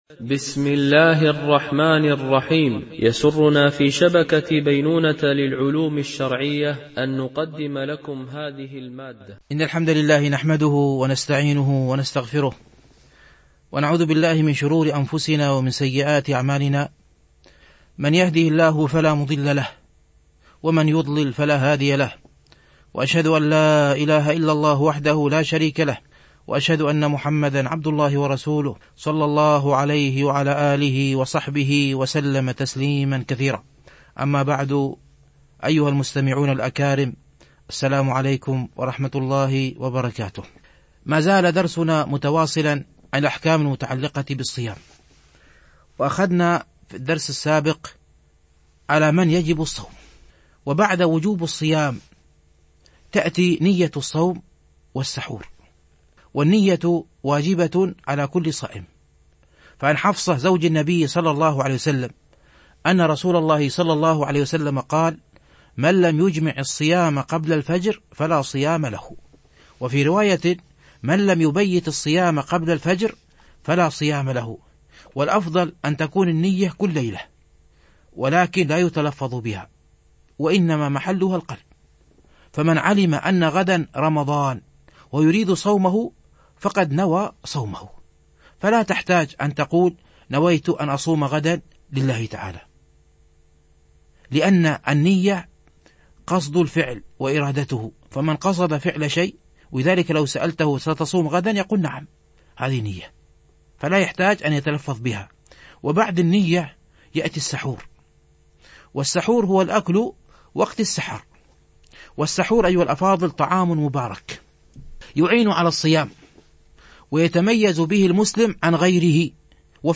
فقه الصائم - الدرس 4